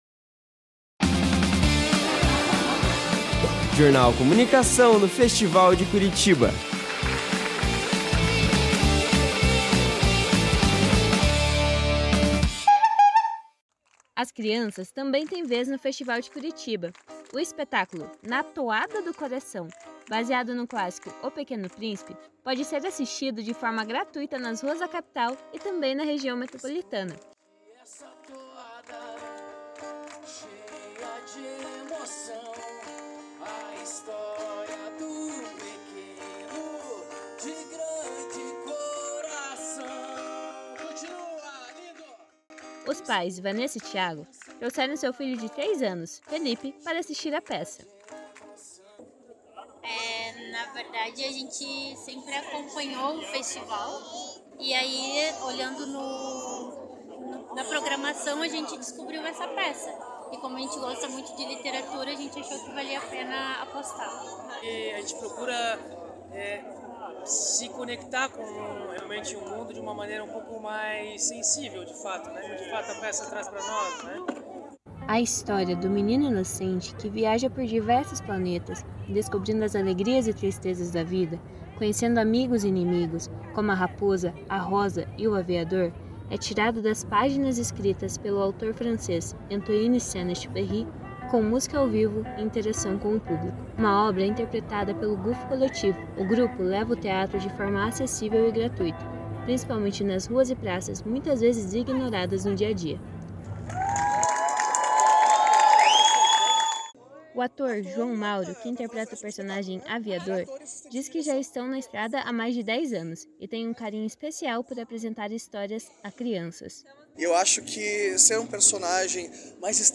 A cobertura da peça pode ser acessada no áudio abaixo. Uma reportagem